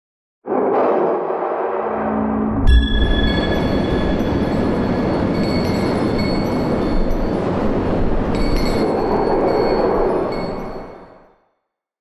abjuration-magic-sign-circle-complete.ogg